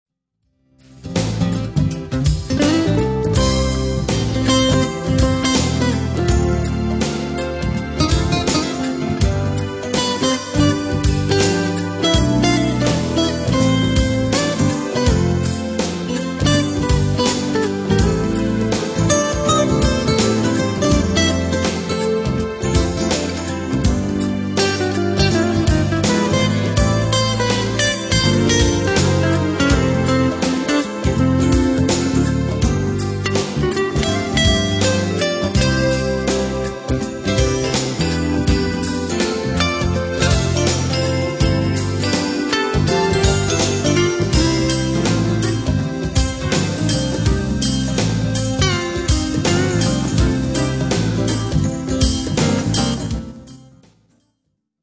Soft Jazz